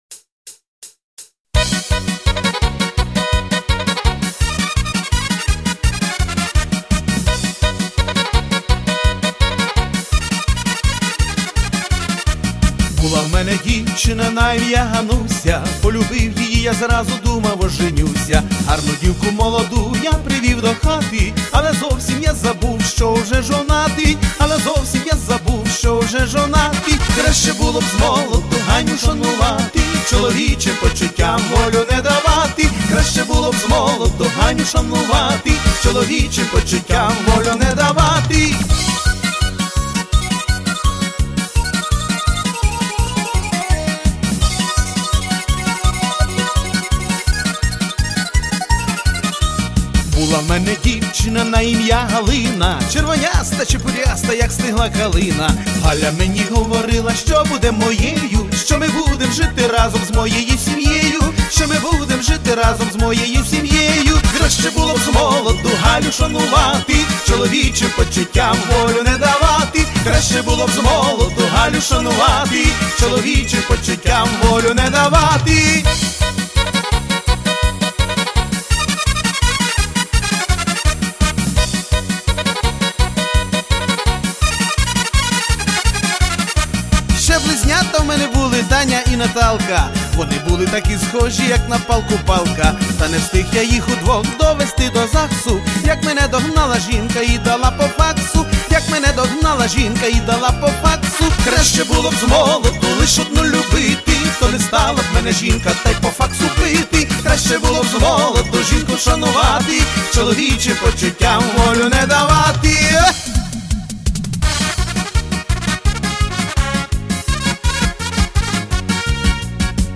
Всі мінусовки жанру Pop-Folk
Плюсовий запис
Яскрава, мелодійна і головне, свіжа пісня на весіллячко!